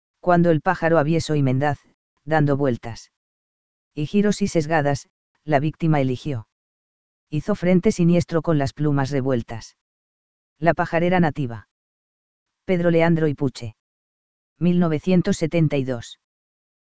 Parabuteo unicinctus unicinctus - Gavilán mixto